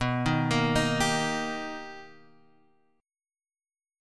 B+M7 chord